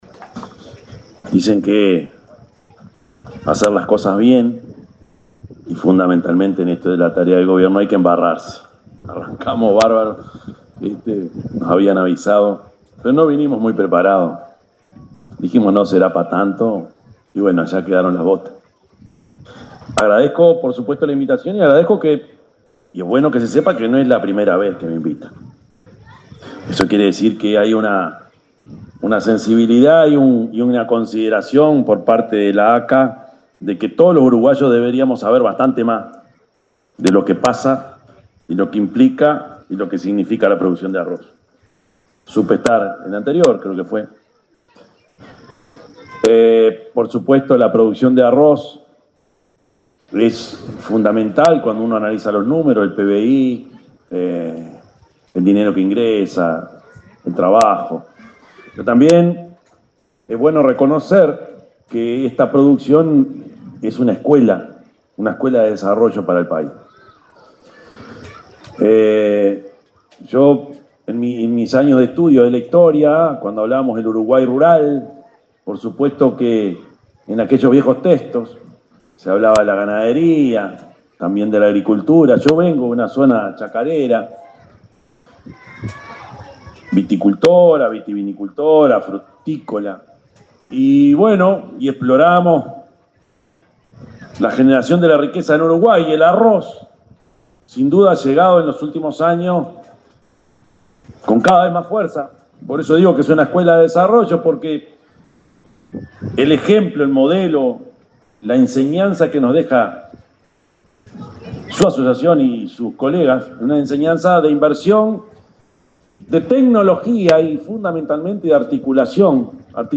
Palabras del presidente de la República, Yamandú Orsi
El presidente de la República, profesor Yamandú Orsi, encabezó el acto de inauguración de la cosecha de arroz 2025 y subrayó que dicha producción es
El evento se realizó este jueves 13 en el departamento de Treinta y Tres.